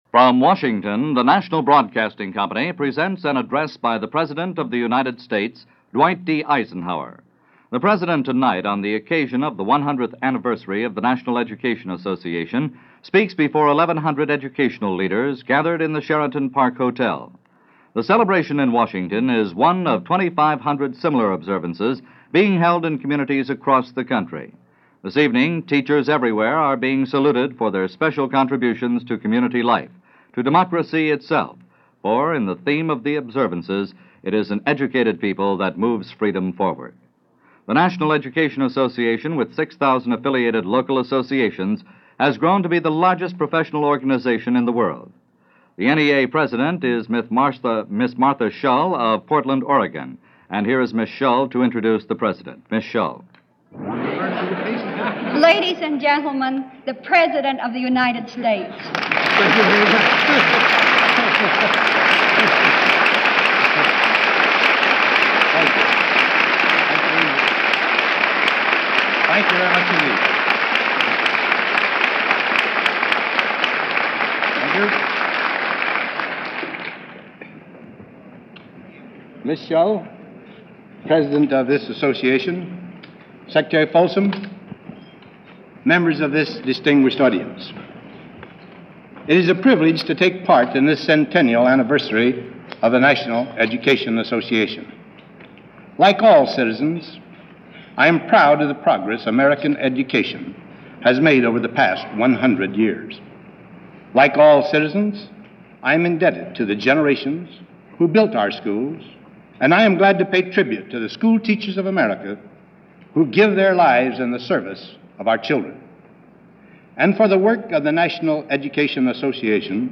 President Eisenhower - Address to National Education Association 1957
President Eisenhower addressed a gathering of the National Education Association, on the occasion of their 100th anniversary in 1957.